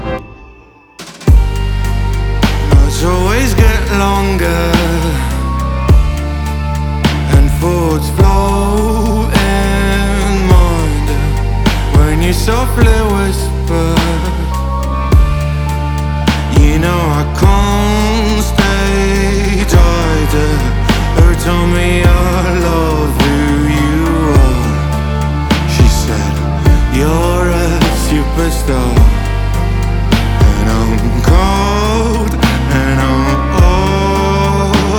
Жанр: Поп / Инди / Альтернатива
# Indie Pop